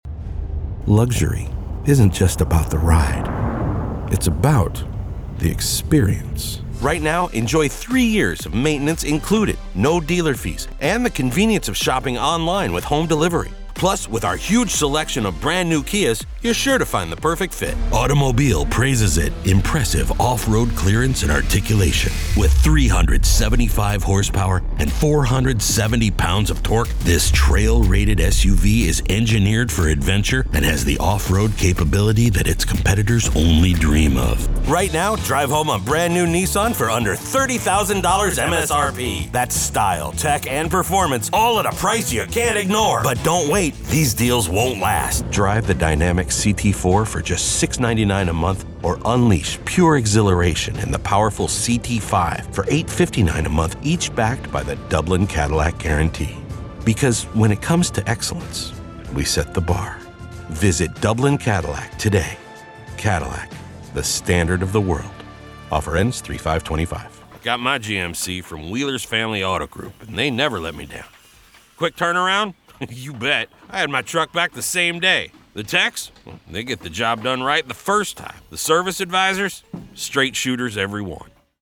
Male
30s, 40s, 50s, 60s
American English (Native)
Assured, Authoritative, Character, Confident, Friendly, Gravitas, Natural, Streetwise, Warm
Commercial, Narration, Animation, IVR or Phone Messaging, Corporate, Video Game, Character, Educational, E-Learning, Documentary, Explainer, Training
Microphone: u87
Audio equipment: Industry-standard recording space, u87 Microphone, Mike Hero interface.